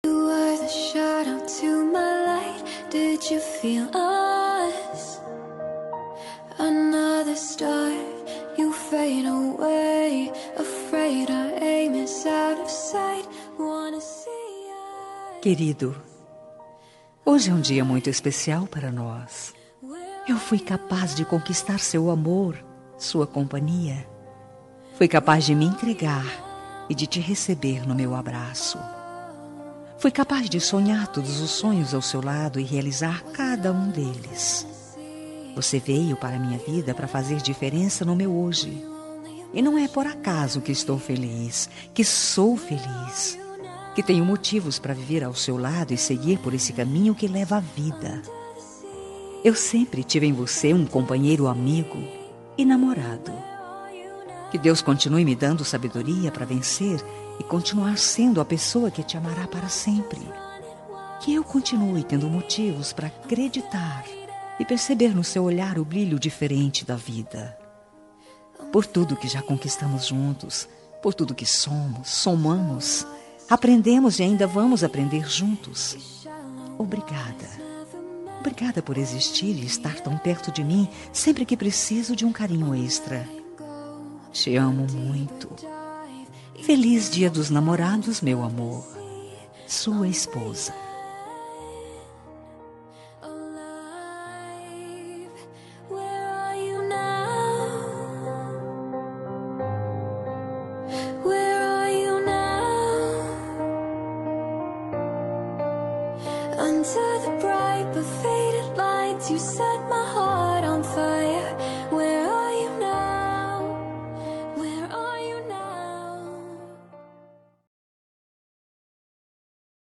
Dia dos Namorados – Para Marido – Voz Feminina – Cód: 6894